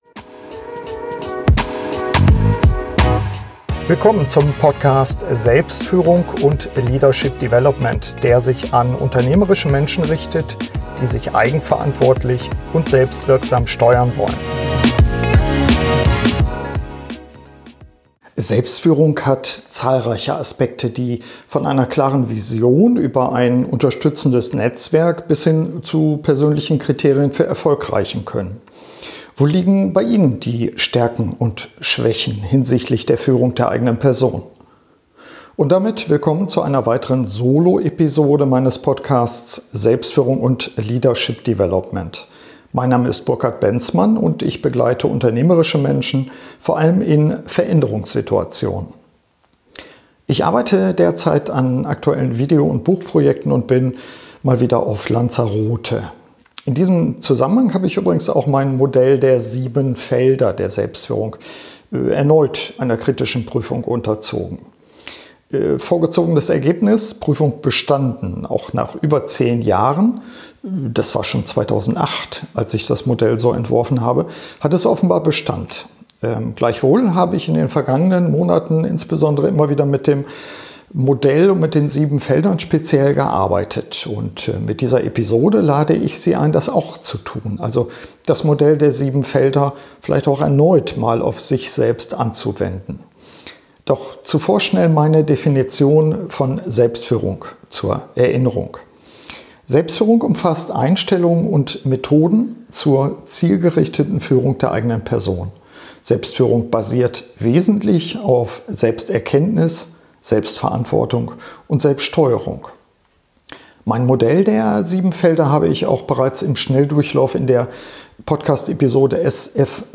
Solo-Episode meines Podcasts biete ich Ihnen für jedes Feld